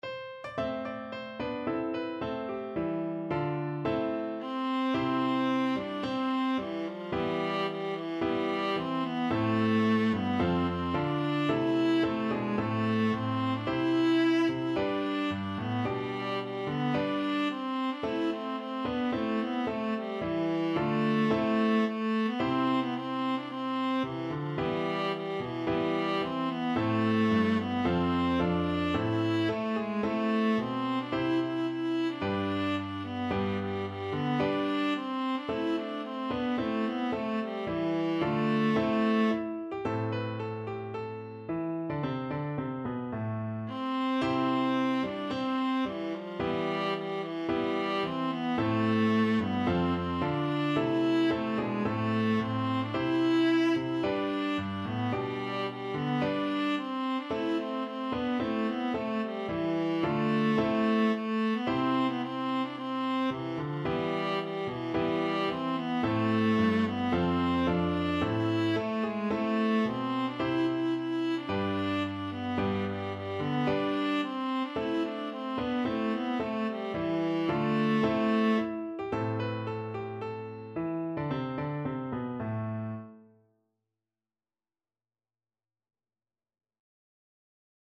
A minor (Sounding Pitch) (View more A minor Music for Viola )
~ = 110 Allegro (View more music marked Allegro)
Viola  (View more Easy Viola Music)
Traditional (View more Traditional Viola Music)